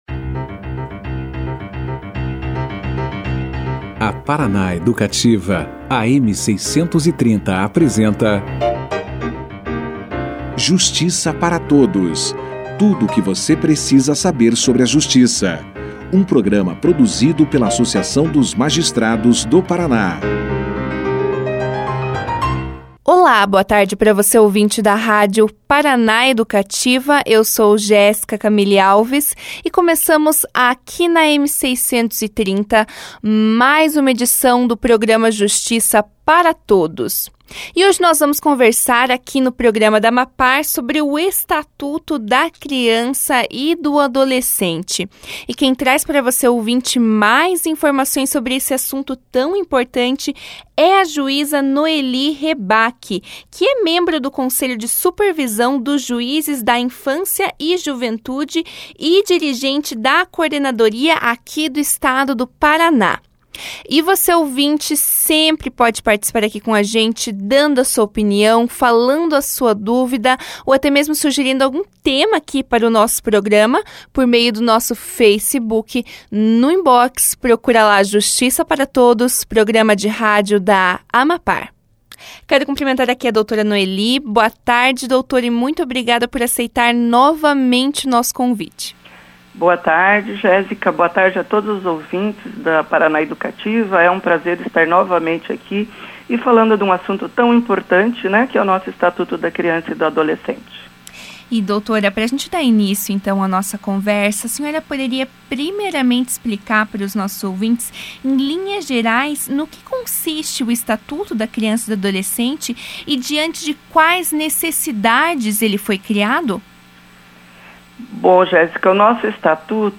Juíza Noeli Reback fala com o Justiça para Todos sobre o ECA
O Estatuto da Criança e do Adolescente foi o tema debatido pelo Justiça para Todos, na terça-feira (26). A juíza Noeli Reback, integrante do Conselho de Supervisão dos Juízes da Infância e Juventude e dirigente da Coordenadoria do Paraná, foi convidada para trazer mais informações e esclarecimentos sobre o tema aos ouvintes da rádio Paraná Educativa.